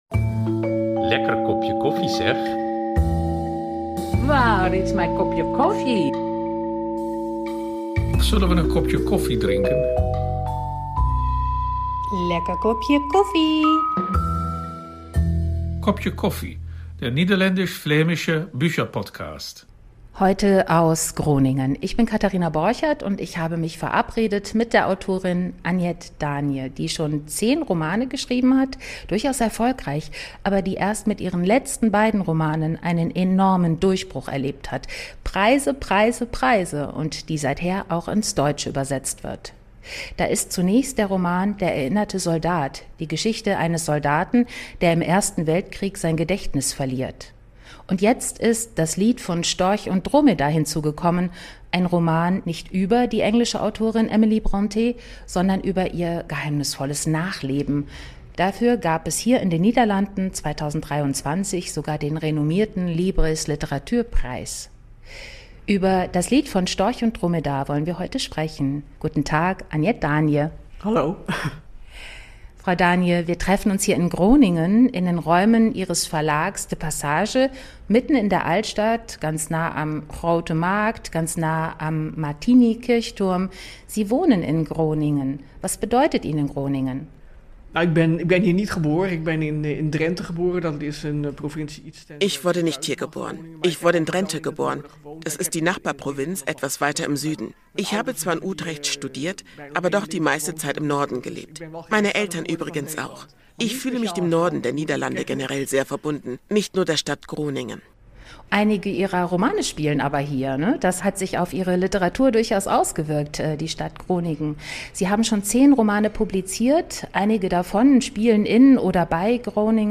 Dabei sind auch Auszüge in Originalsprache und viel Persönliches von ihren Gästen zu hören. Der deutsche Bücherpodcast macht Lust und Laune auf eine Entdeckungsreise durch die aktuelle niederländischsprachige Literaturszene.